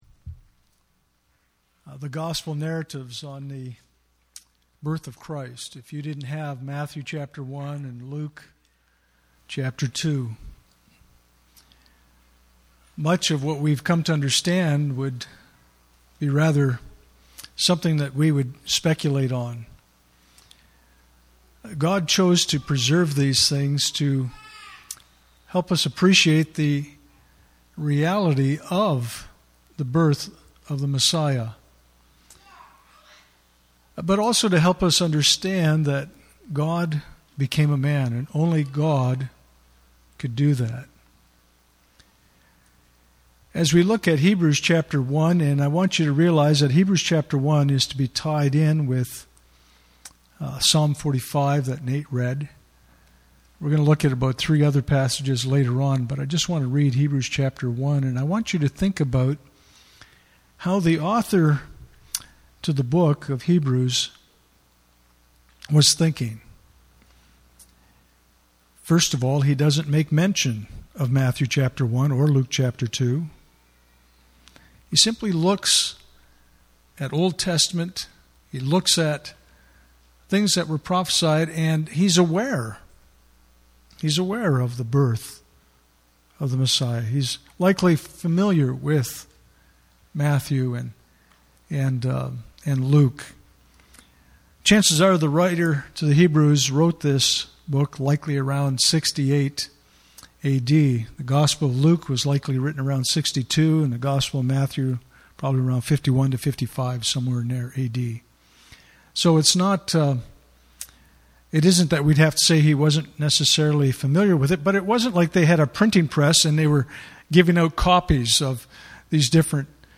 Communion Service
Communion Passage: Psalm 45:1-17 Service Type: Sunday Morning « Did You Really Expect the Messiah to Come in Royal Pageantry?